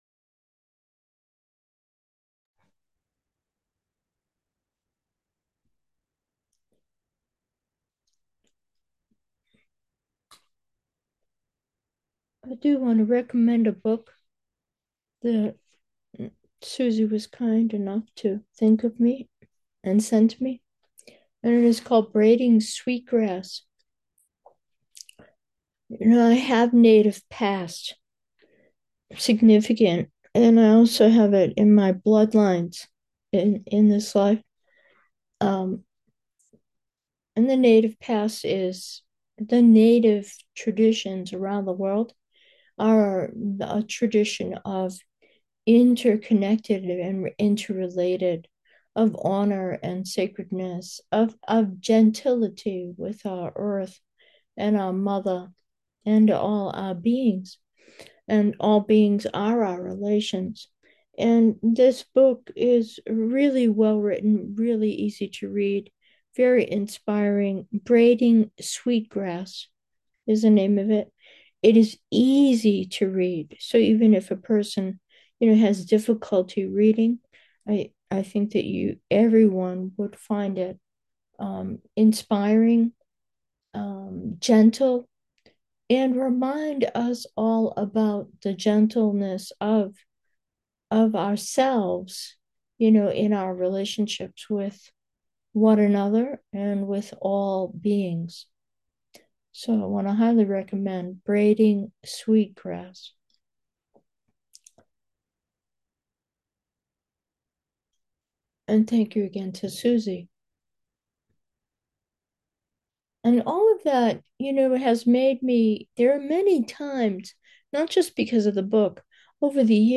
Meditation: fresh, turtle-ness